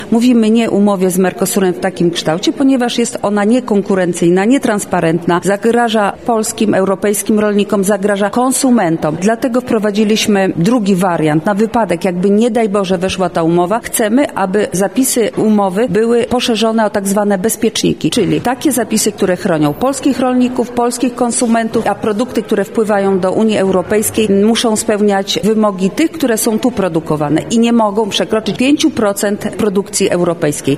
Marta Wcisło – mówiła dzisiaj (22.12) europosłanka Marta Wcisło podczas Wojewódzkiej Rady Dialogu Rolniczego.